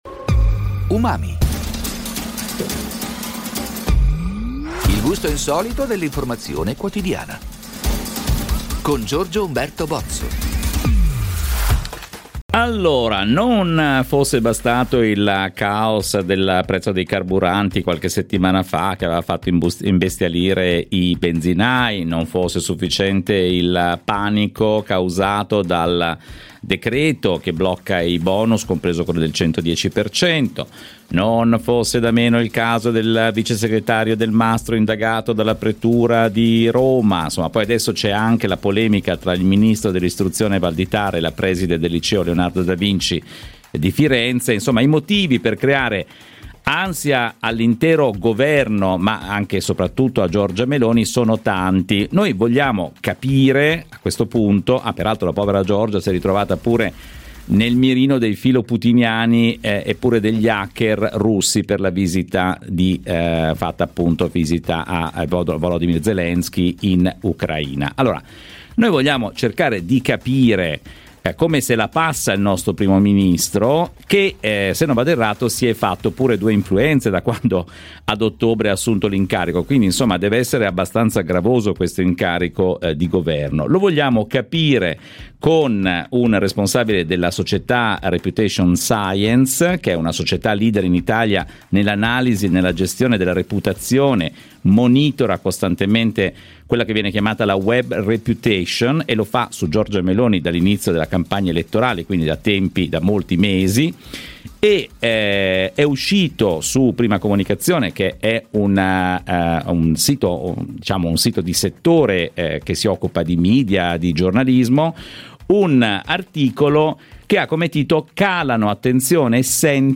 La mia intervista su Giornale Radio sulla reputazione online di Giorgia Meloni nel corso dei suoi primi 100 giorni di governo.